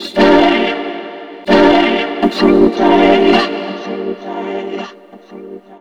10-Vocode It.wav